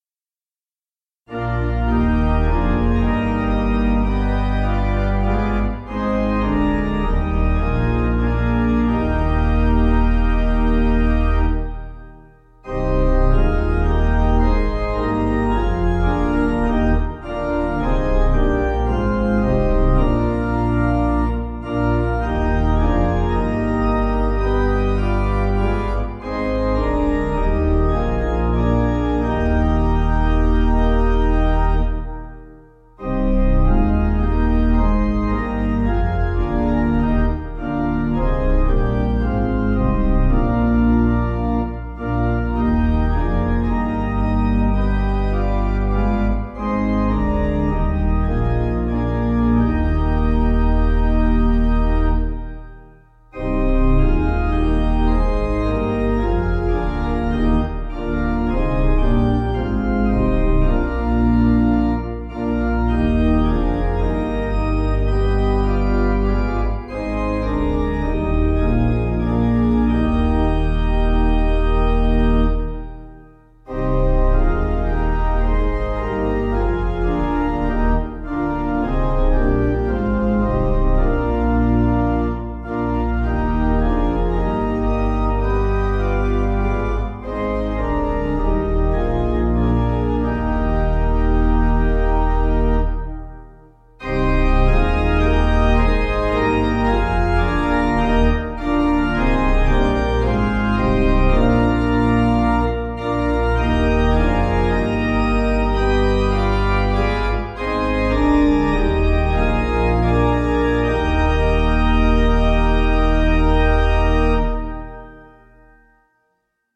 (CM)   5/Eb 483.3kb